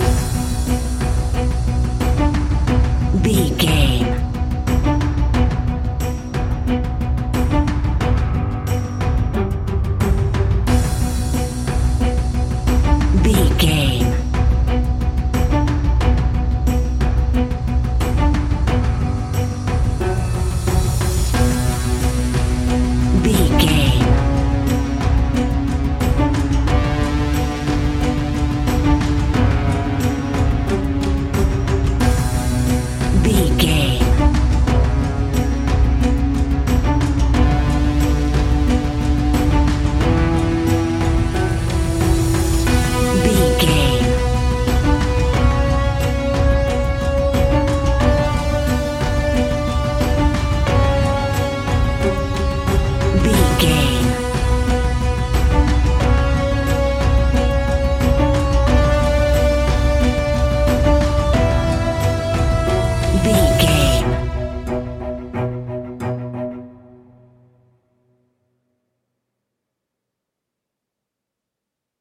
In-crescendo
Thriller
Aeolian/Minor
ominous
dark
eerie
strings
percussion
piano
ticking
electronic music
Horror Synths